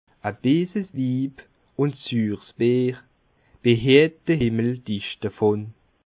Bas Rhin
Ville Prononciation 67
Schiltigheim